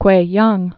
(kwāyäng)